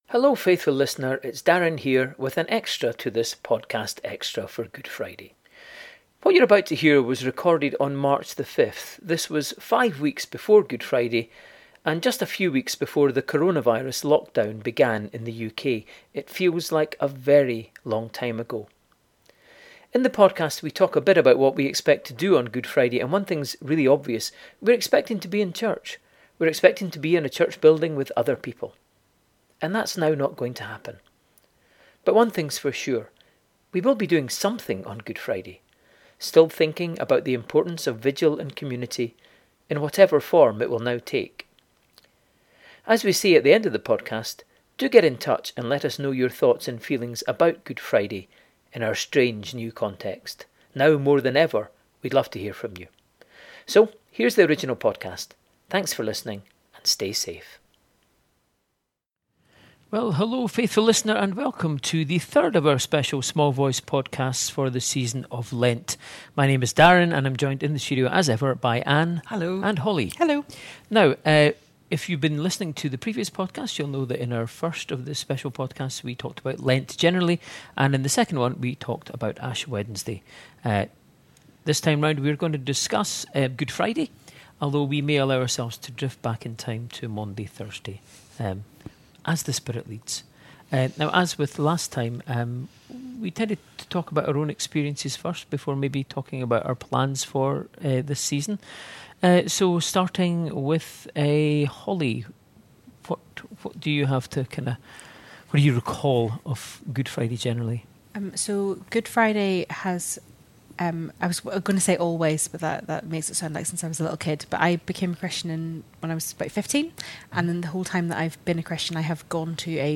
Recorded on 5 March, before coronavirus lockdown in Scotland, some of what the team talk about now won’t happen, or at least in the form we expected…. Many of the themes will still resonate: what is vigil and community?